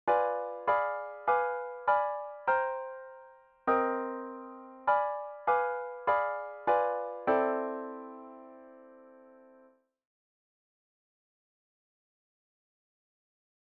The diminished color is based on the minor 3rd, so we can whole step, half step it or move by three frets for the minor 3rd interval. Moving the one shape up and down the neck is the neat trick here.